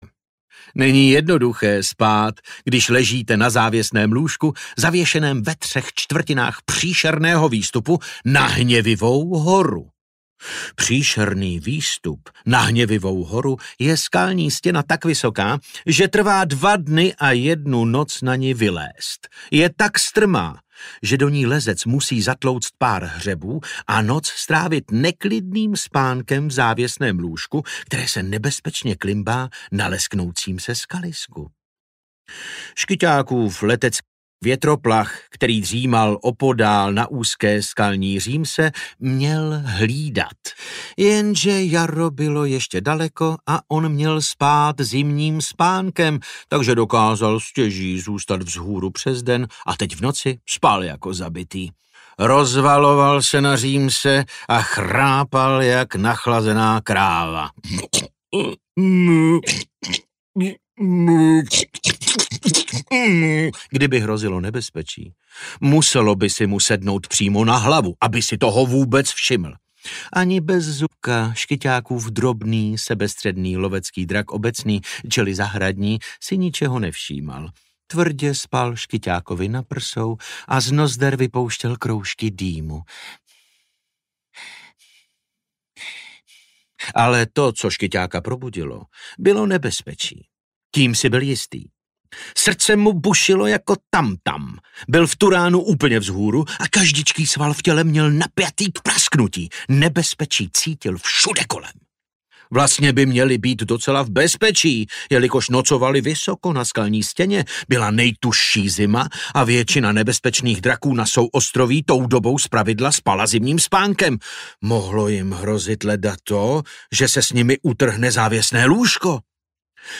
Jak uloupit dračí meč audiokniha
Ukázka z knihy
• InterpretDavid Novotný